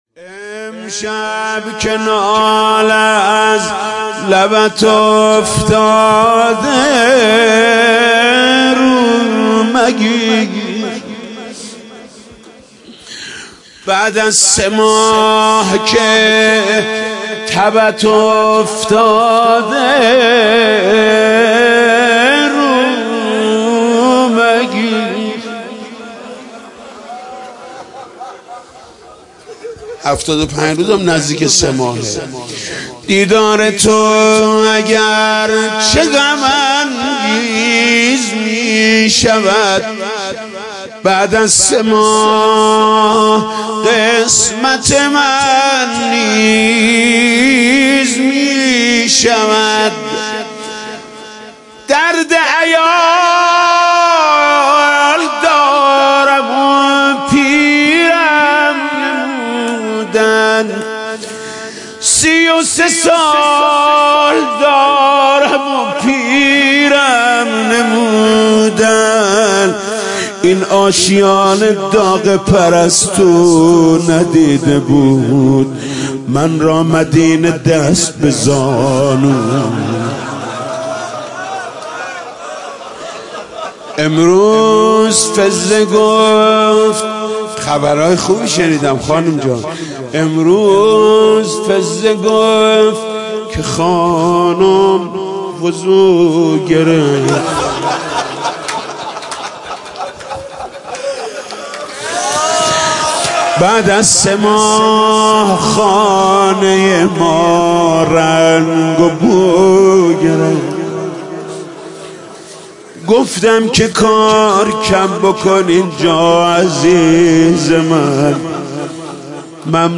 دانلود مداحی شهادت حضرت زهرا (س) شب چهارم ایام فاطمیه بهمن96 حاج محمود کریمی